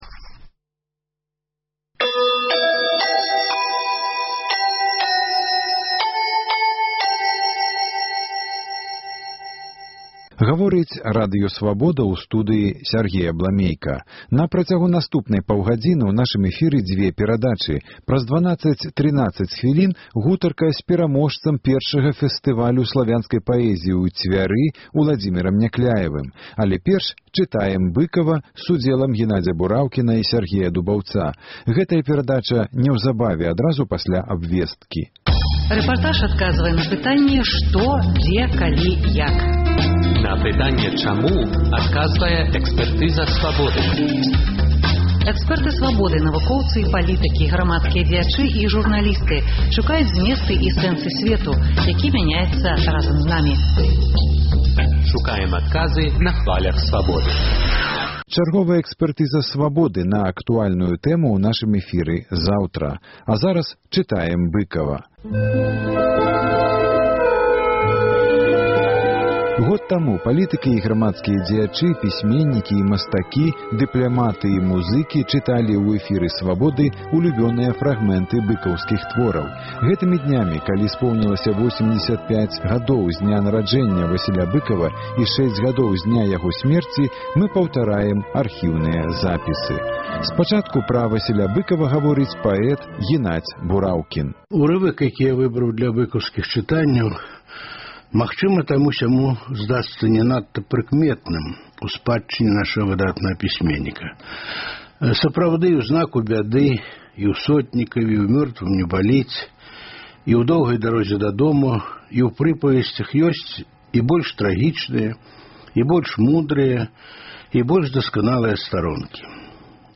Размова з паэткай Данутай Бічэль пра Васіля Быкава, развагі Аляксандра Фядуты пра кнігу перапіскі Рыгора Барадуліна з маці “Паслаў бы табе душу" і гутарка з Уладзімерам Някляевым і ягоныя новыя вершы ў аўтарскім чытаньні